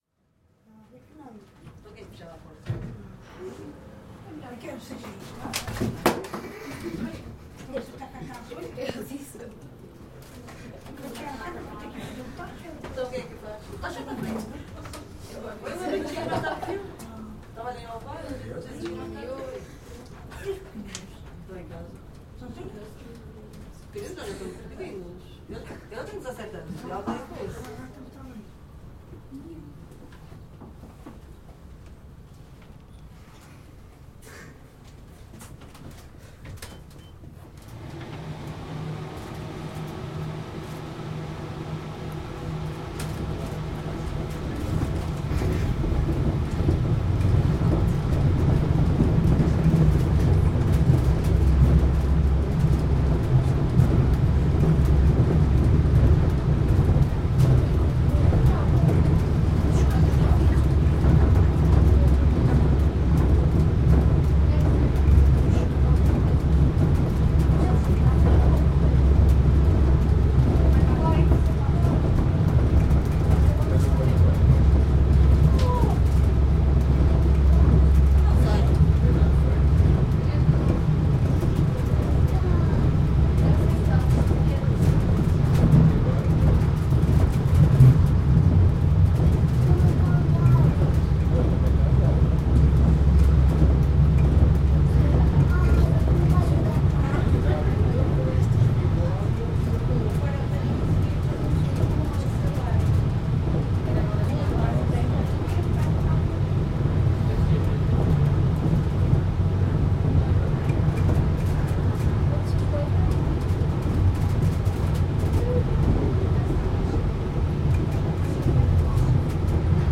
Registo do ambiente dentro de um funicular. Gravado com Edirol R44 e um par de microfones de lapela Audio-Technica AT-899.
Tipo de Prática: Paisagem Sonora Rural
Viseu-Calçada-do-Viriato-Dentro-do-funicular.mp3